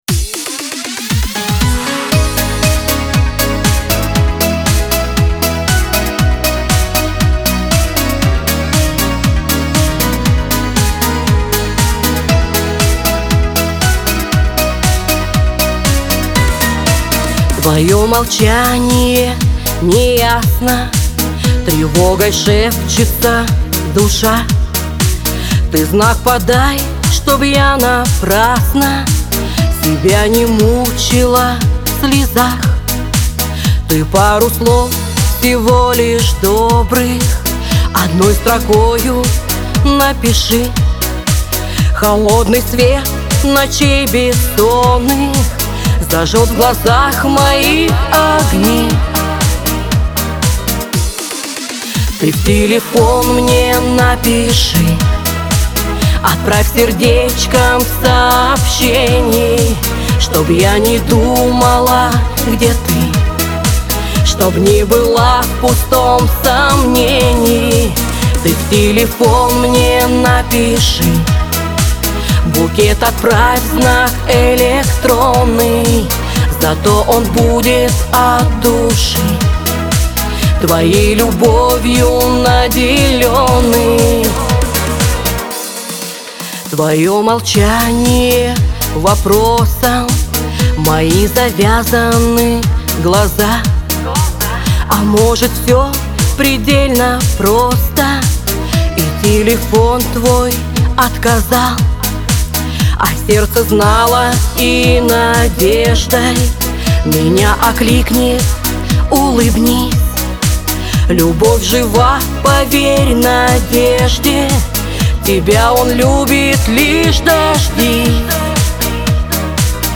Лирика , эстрада , pop , диско